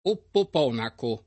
oppoponaco [ oppop 0 nako ] → opoponaco